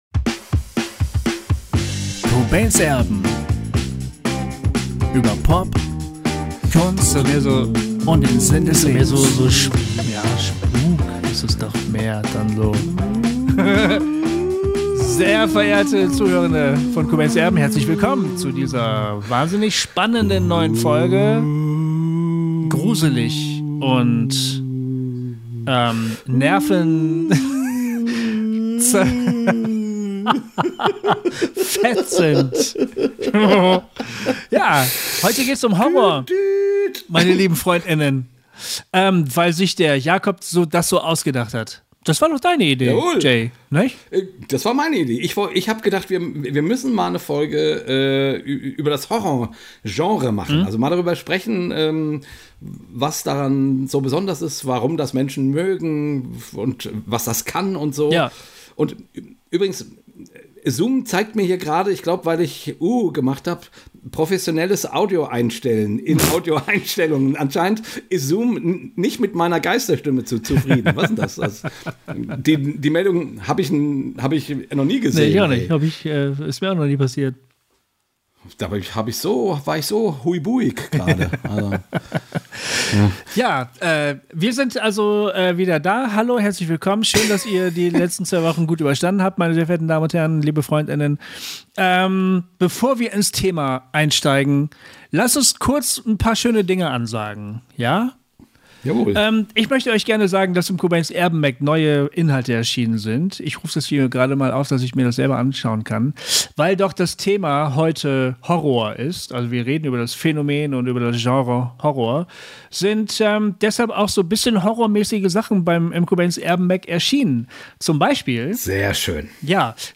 Aber gerade das ist ein spannendes Detail, dem wir in diesem Gespräch nachgehen: Warum wenden wir uns vom realen Horror ab, dem fiktiven aber zu? Warum kann es sogar sinnvoll sein, dass wir uns mit unseren Ängsten konfrontieren? Wir reden über Horror als Genre und darüber, warum wir glauben, dass es manches einfach besser kann als andere Genres.